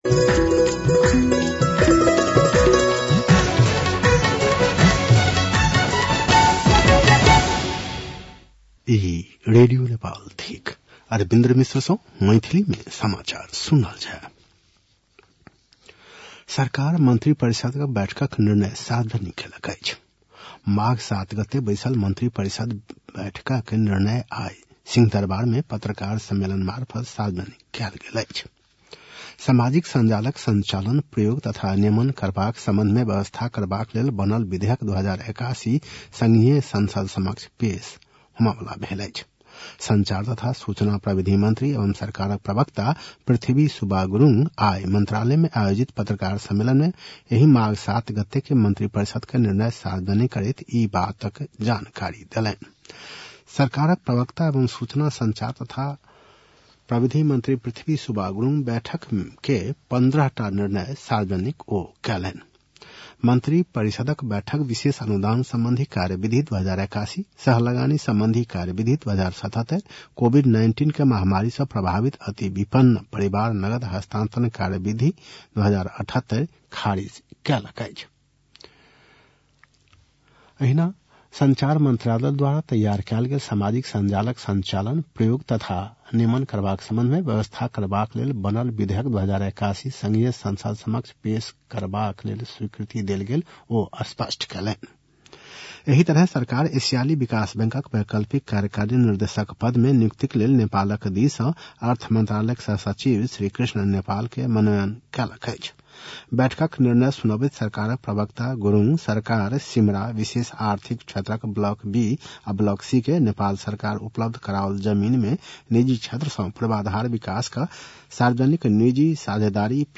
बेलुकी ७ बजेको नेपाली समाचार : १० माघ , २०८१